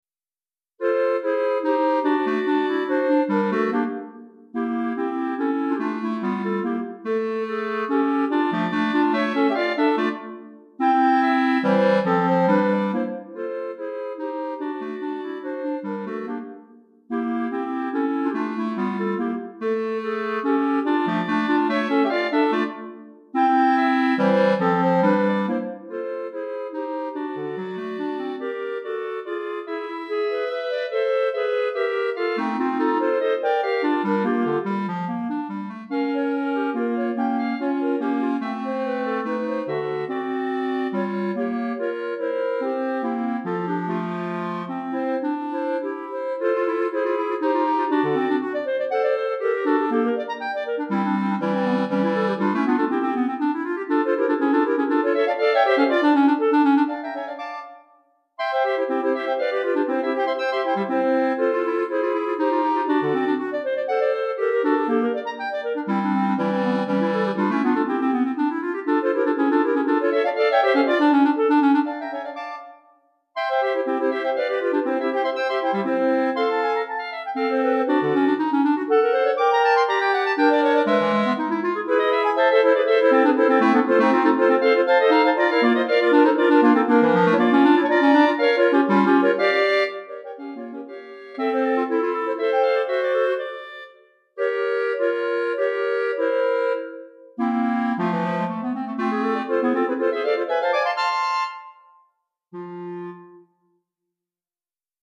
3 Clarinettes